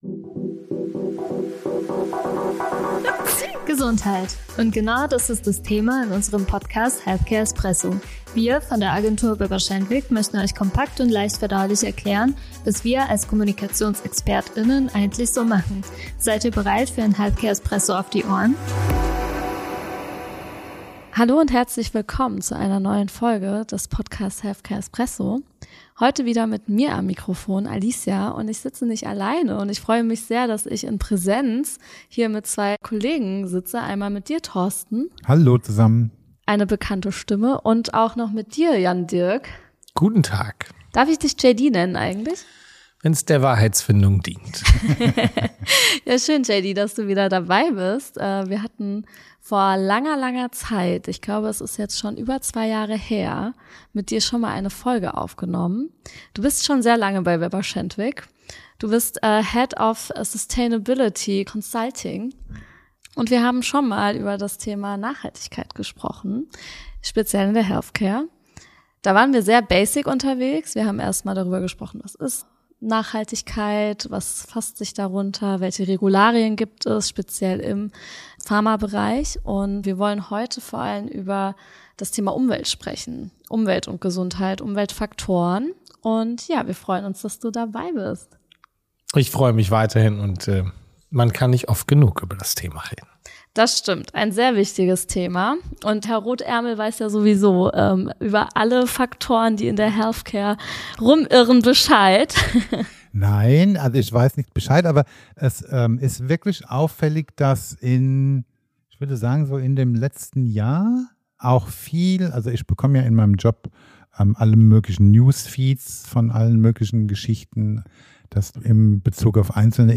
Im Podcast Healthcare EsPResso beleuchten unterschiedliche Mitarbeitende der Agentur Weber Shandwick einfach und verständlich interessante Themen der Health-Branche, geben Einblicke in ihren Arbeitsalltag und diskutieren über aktuelle Entwicklungen und Trends der Kommunikationswelt.